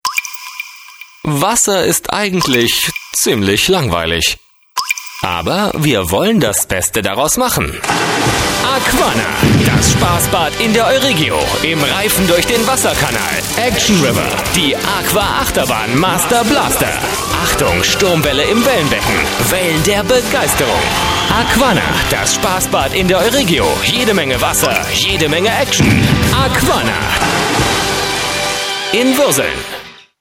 Jung-dynamische Werbestimme, Radiomoderator, 39 Jahre
Sprechprobe: Sonstiges (Muttersprache):
german young voice over artist